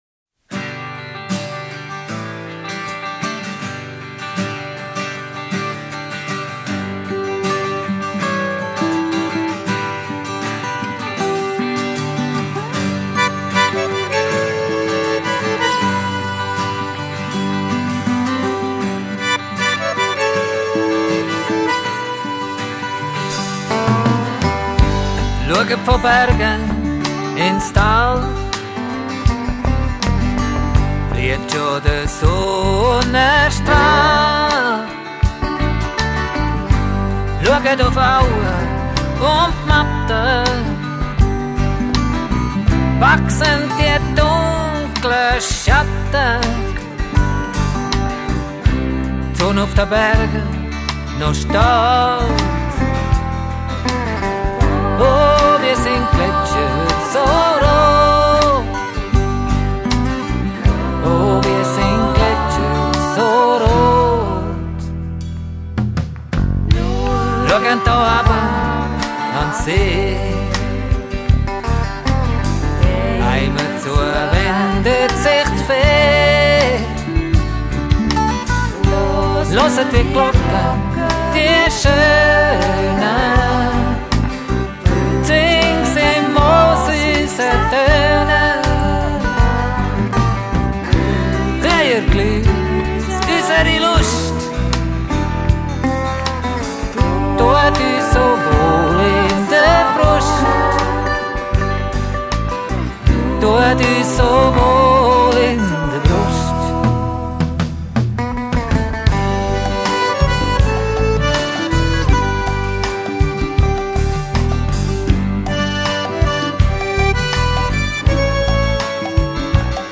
(abendlied der wehrliknaben)
gitarre
schlagzeug
tönen wunderbar frisch und zeitlos   » weiter